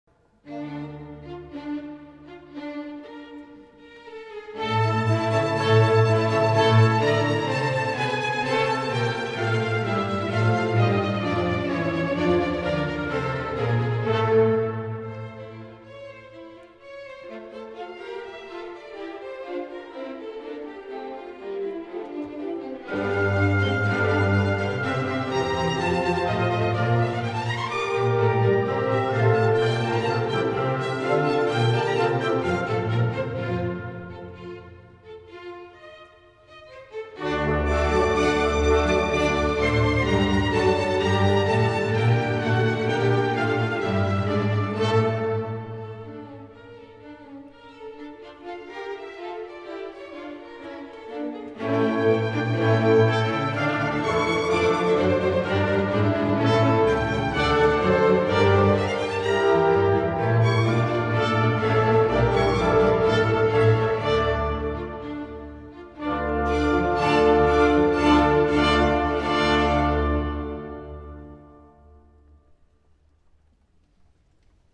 Auszüge aus Konzert Aufnahmen:
W.A.Mozart, „La Betulia Liberata“, Azione sacra KV118 | Konzertmitschnitte aus dem Jahr 2006